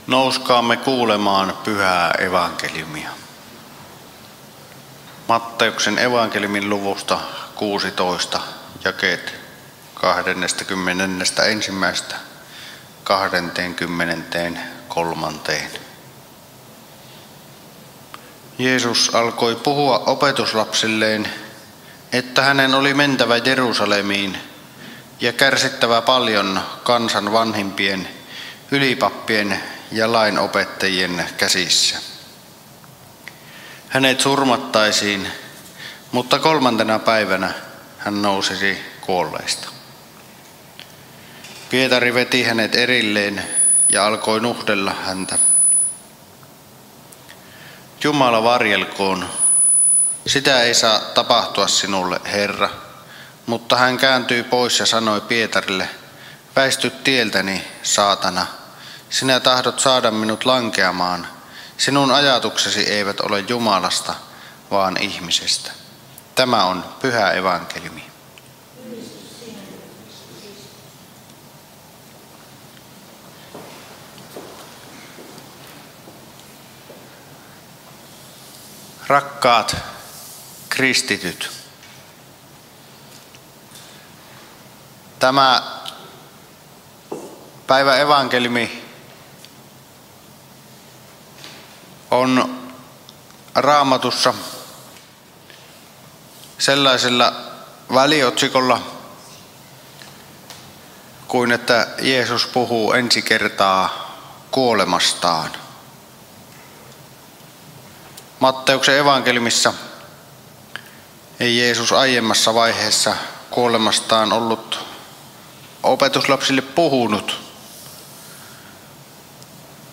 Kälviällä 1. paastonajan sunnuntaina Tekstinä Matt. 16:21–23